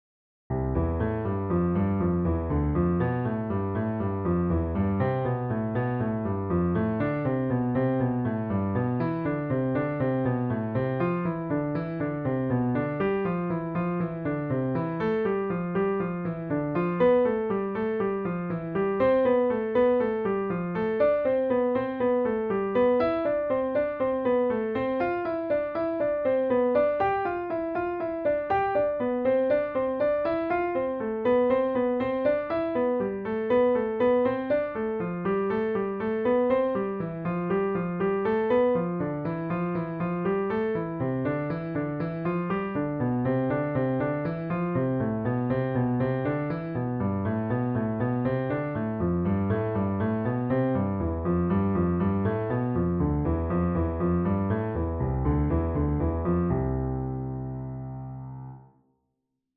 piano exercises